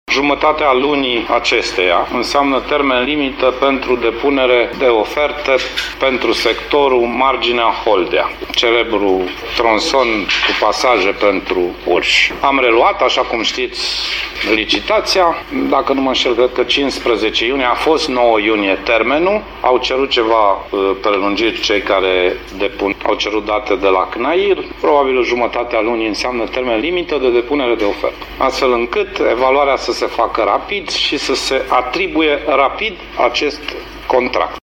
Ministrul Transporturilor, Sorin Grindeanu a anunțat astăzi, la Timișoara, că licitația va fi organizată la jumatatea acestei luni.
Sorin-Grindeanu-autostrada.mp3